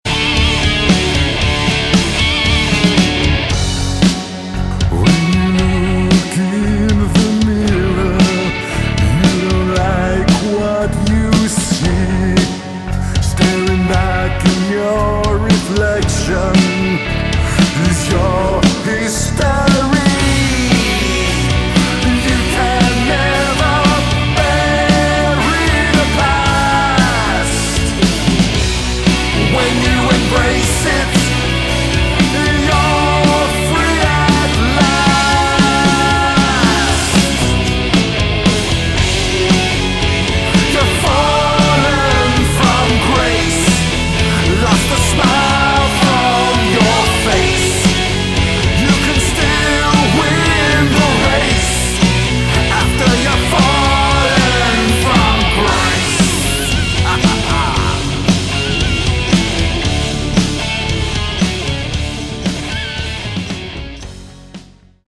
Category: Melodic Metal
vocals, keyboards, guitars
rhythm and lead guitars
backing vocals